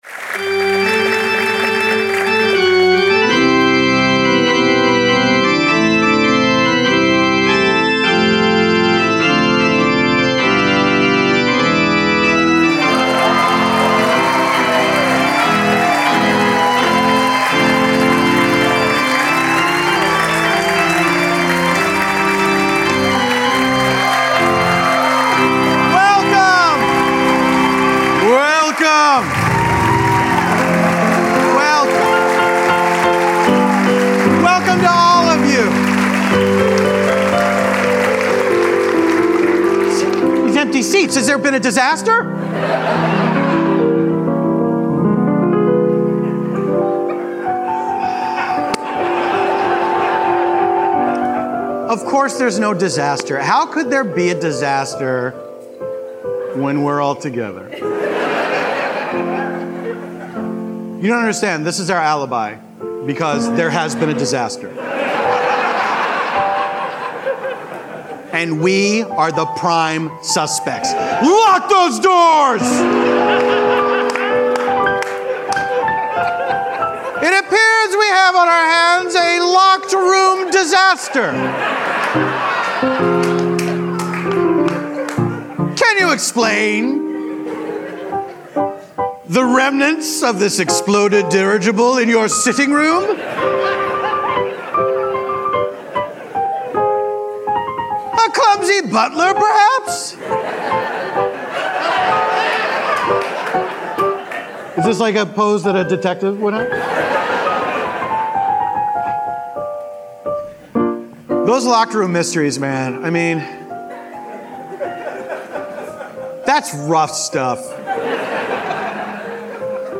Then, they are joined by improvisers Eugene Cordero, Tawny Newsome, and Little Janet Varney, to improvise a story set in Germany.
Germany: Live from Philly